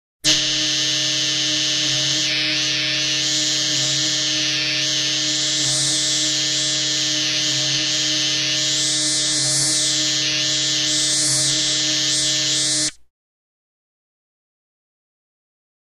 Buzz 2; Multi-pitched, Oscillating, Processed Buzz. Lower Pitched Than FX 18.